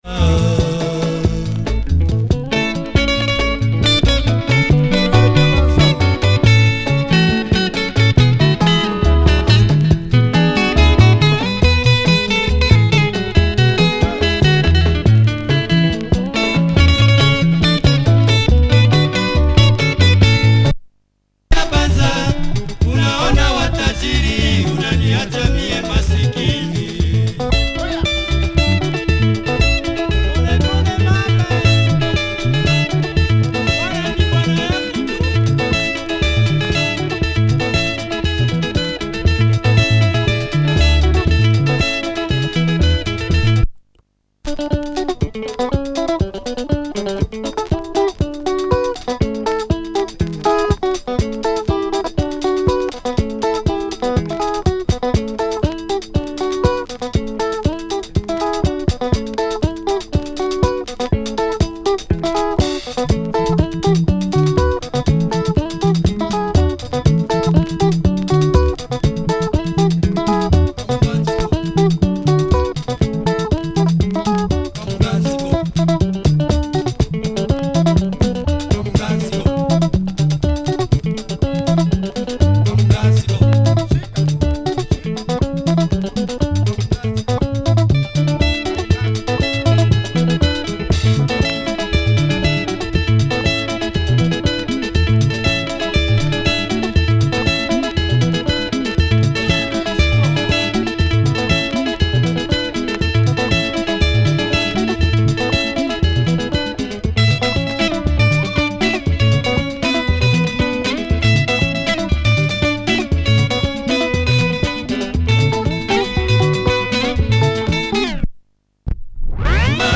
Lingala
Vinyl is clean.